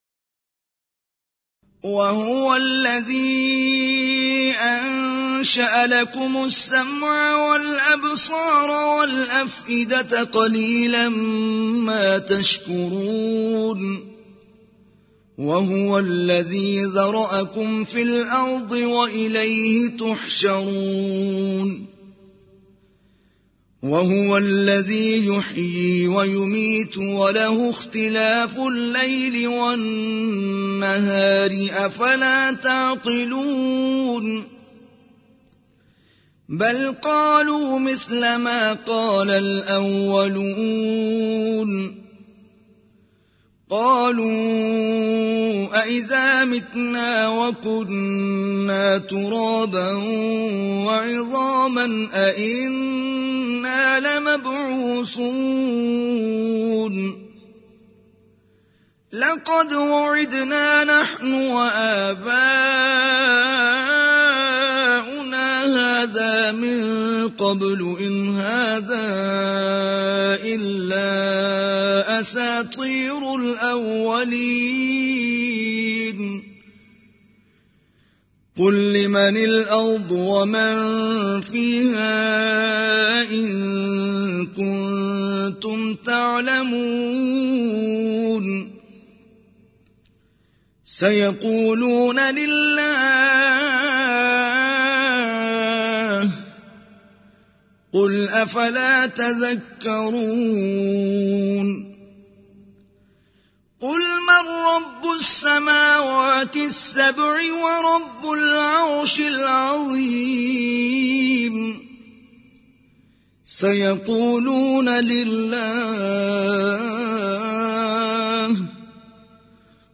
Complete Quran Recitation [Arabic] Surah Mominoon 1